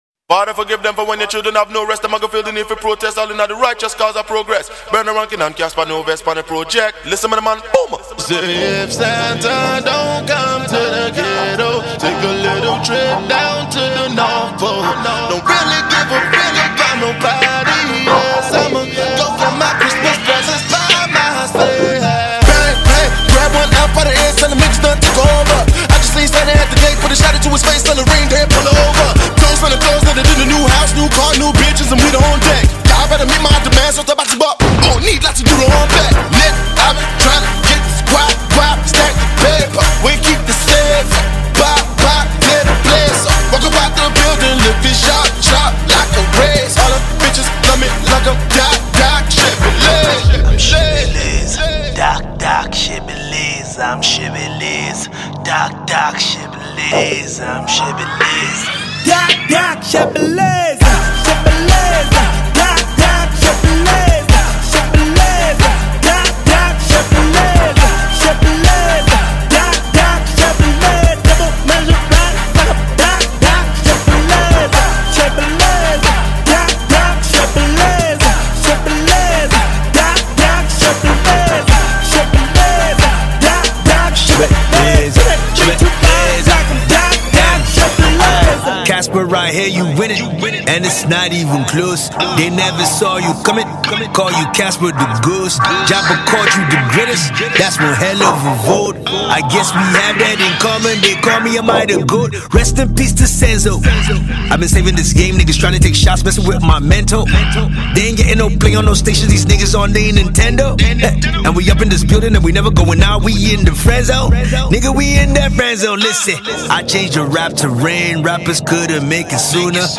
Hip-Hop
Buzzing South African Rapper